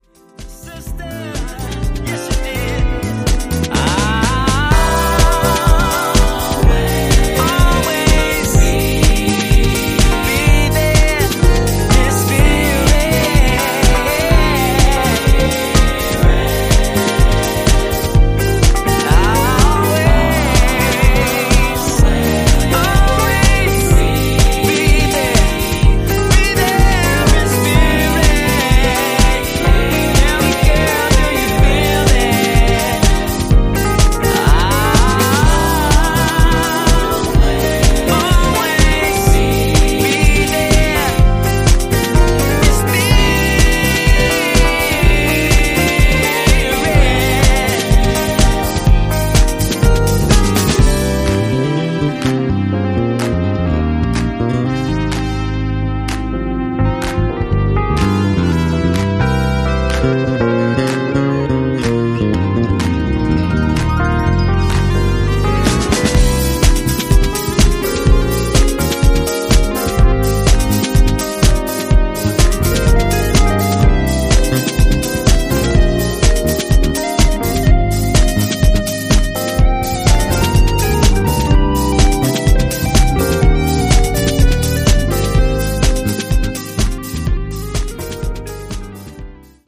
vocal-led, groove-driven house music